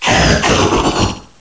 pokeemerald / sound / direct_sound_samples / cries / uncomp_zarude.aif
uncomp_zarude.aif